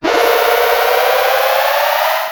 RetroGamesSoundFX
Teleport2.wav